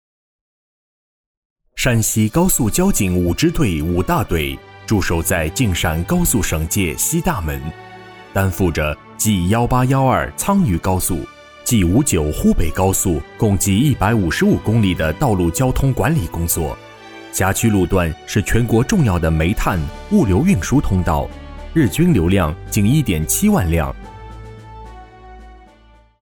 男679-专题-党建微课堂
男679中英双语配音 679
男679-专题-党建微课堂-.mp3